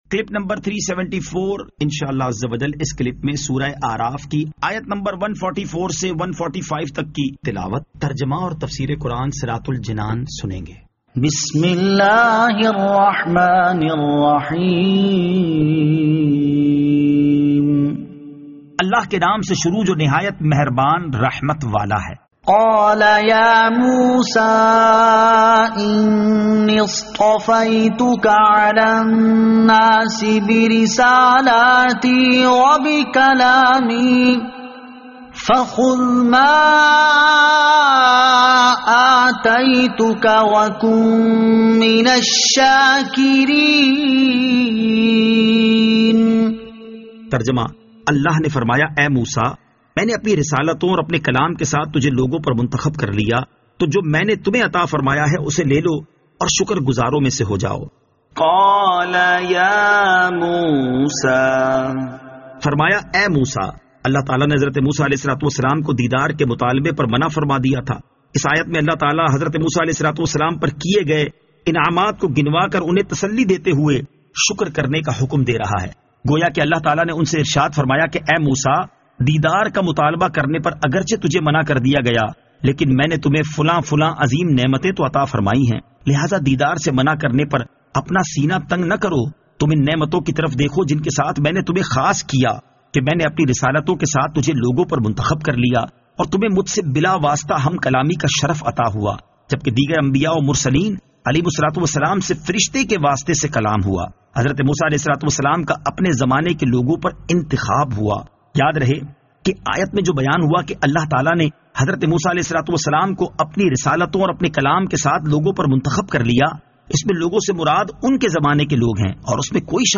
Surah Al-A'raf Ayat 144 To 145 Tilawat , Tarjama , Tafseer